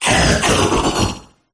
zarude_ambient.ogg